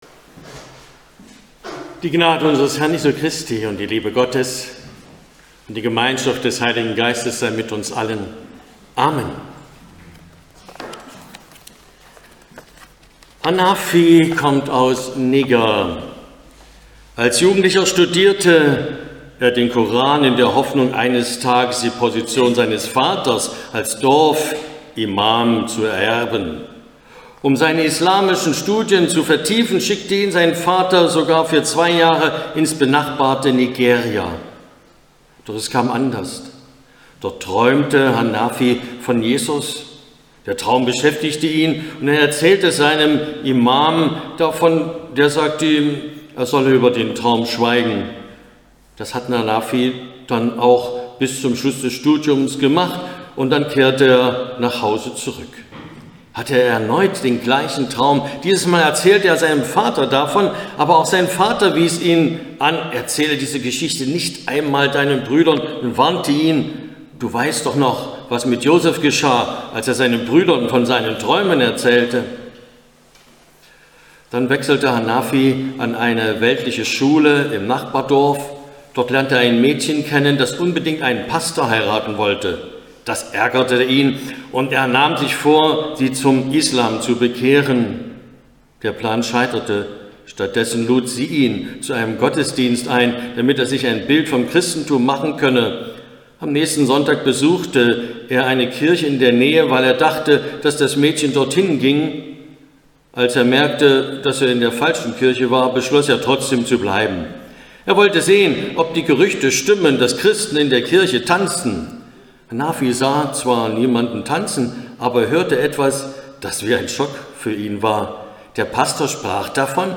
Predigt 21.04.2024